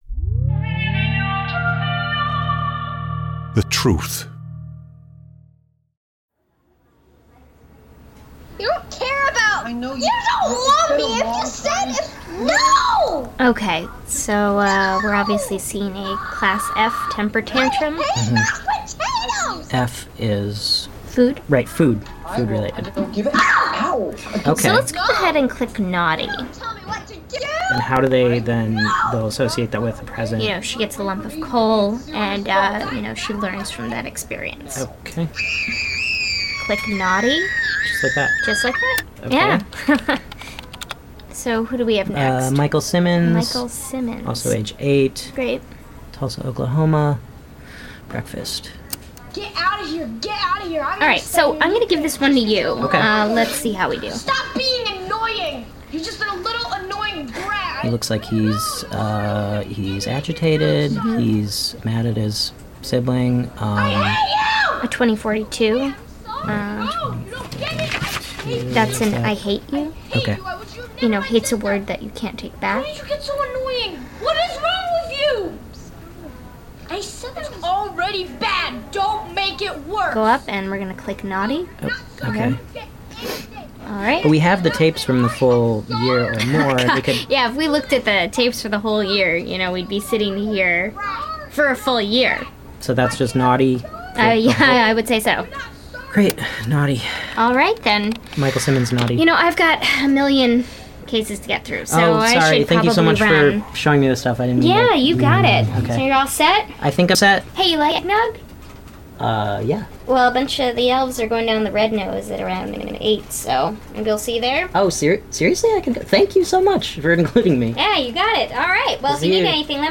• • Today's audio drama is from, The Truth podcast. Xmas themed of course.